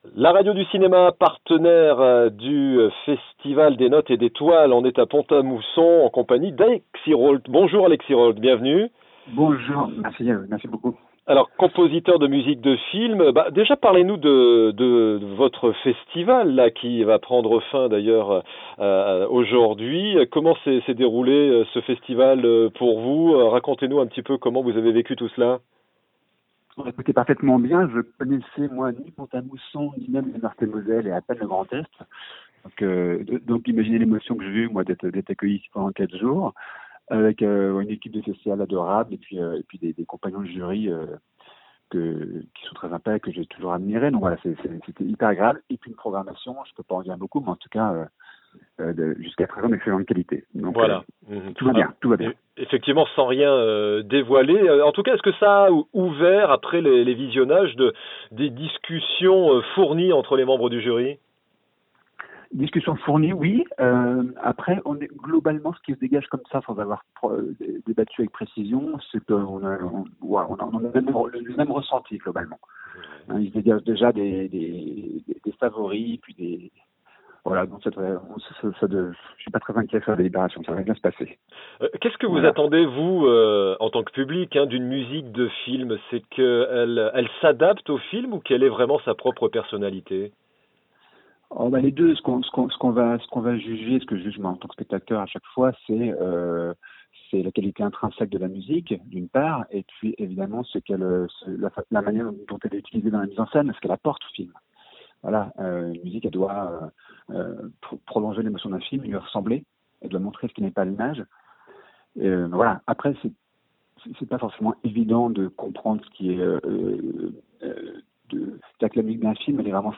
Des notes et des Toiles L'interview